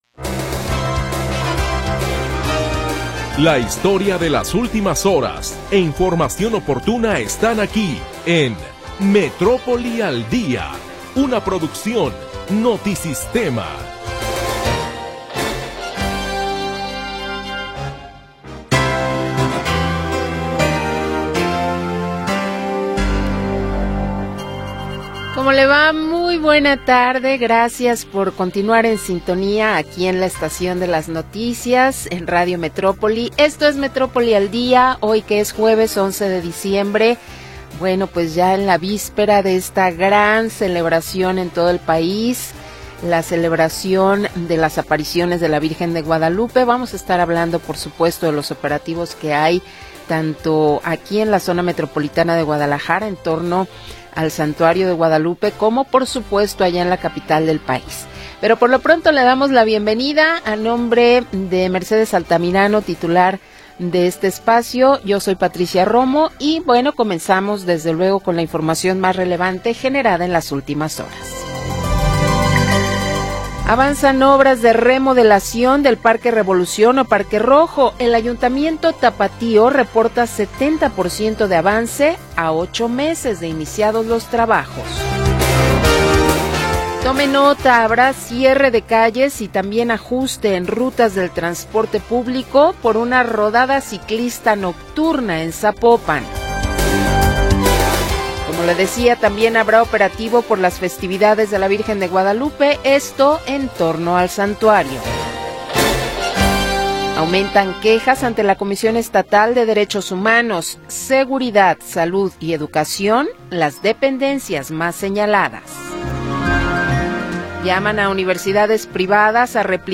Primera hora del programa transmitido el 11 de Diciembre de 2025.